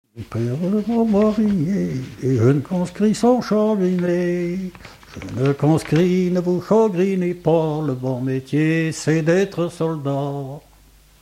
Mémoires et Patrimoines vivants - RaddO est une base de données d'archives iconographiques et sonores.
Refrain de conscrits
Chants brefs - Conscription
Pièce musicale inédite